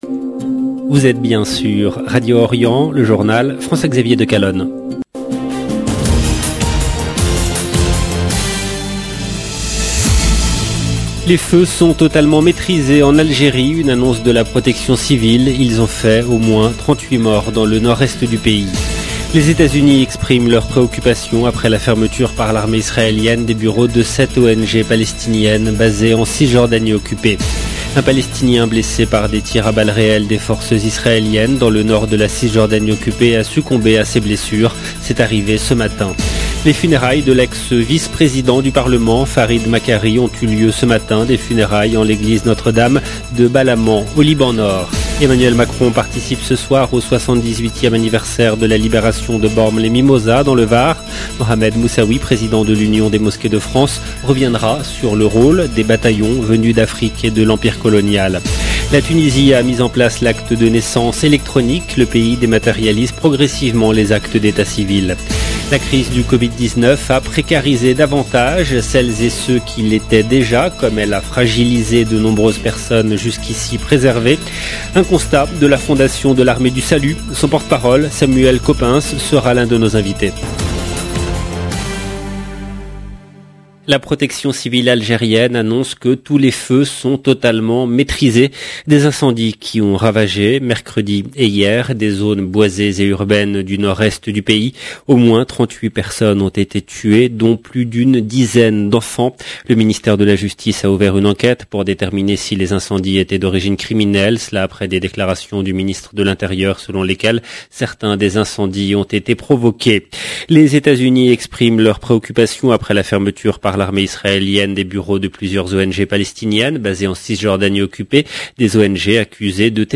EDITION DU JOURNAL DU SOIR EN LANGUE FRANCAISE DU 19/8/2022